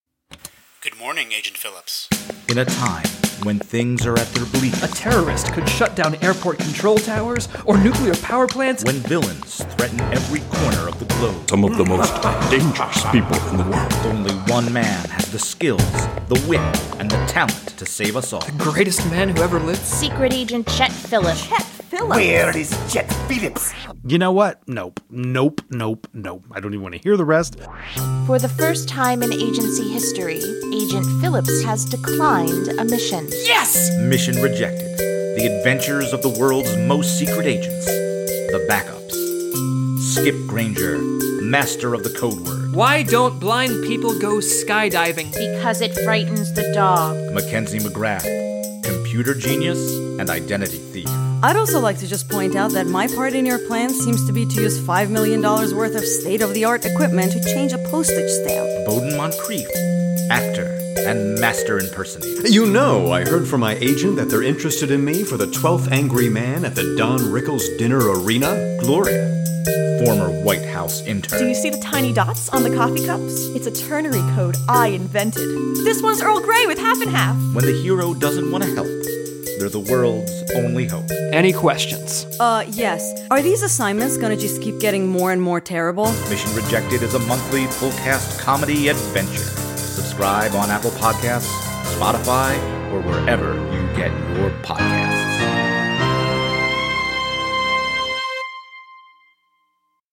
This House Will Devour You is a story of love, madness, mysteries and dead gods, as told by Jon and Elizabeth to each other in their letters. For lovers of creepy, slow-burn historical fiction, THWDY is an epistolary audio drama set between England and the uneasy peace of post-Civil War Ireland. Newly arrived Captain Jon Ross must survive both local hostility and the increasingly sinister happenings at Kilphaun Hall, County Waterford, as his fiancé Elizabeth Sanderson risks London’s occultist demi-monde in a race to save him.